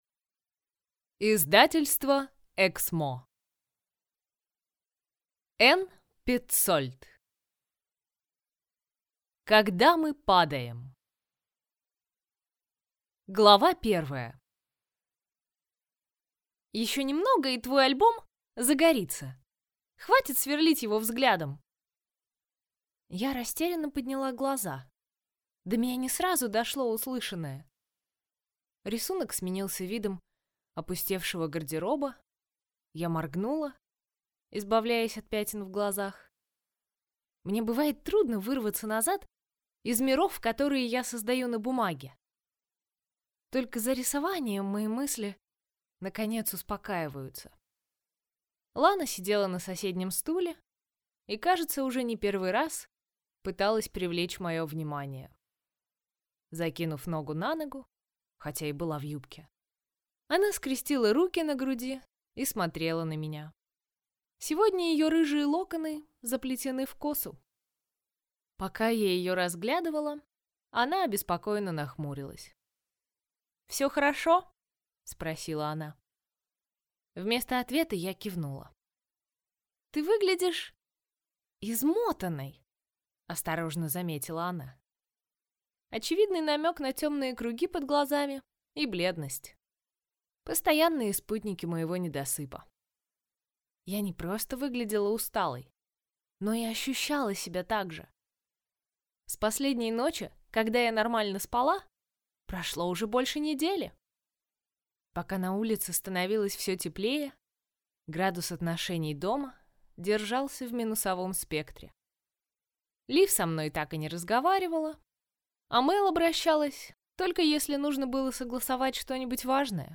Аудиокнига Когда мы падаем | Библиотека аудиокниг
Прослушать и бесплатно скачать фрагмент аудиокниги